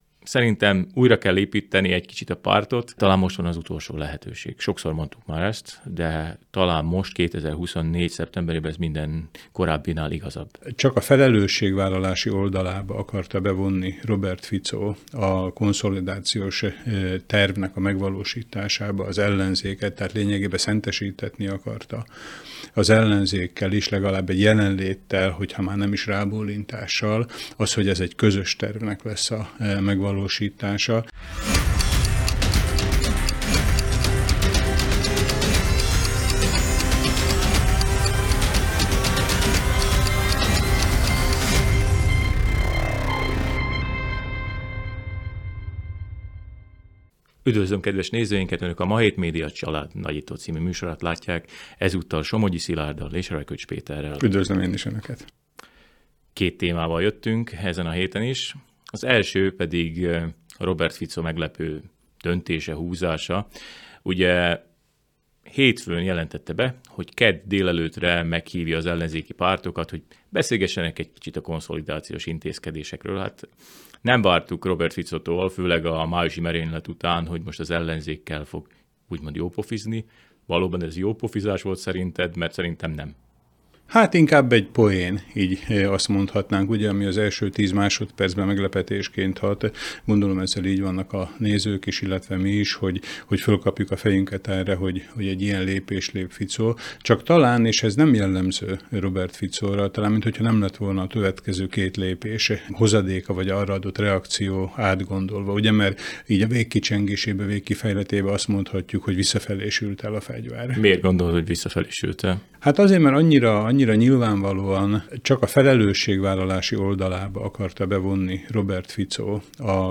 A héten a parlamenti politikában és a felvidéki magyar párt szintjén is eseményekkel teli időszak vár ránk, amiről a Nagyító stúdiójában két munkatársunk beszélgetett.